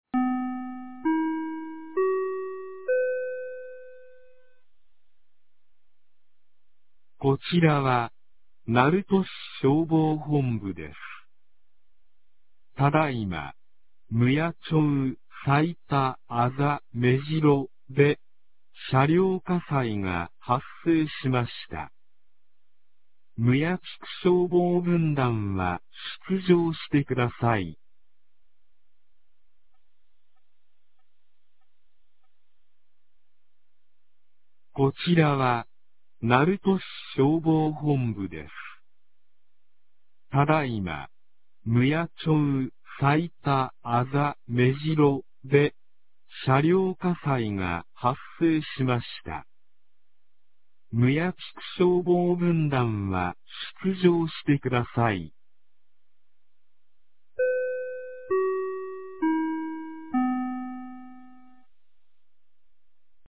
2025年03月08日 20時55分に、鳴門市より大麻町-川崎、大麻町-津慈、大麻町-萩原、大麻町-板東、大麻町-桧へ放送がありました。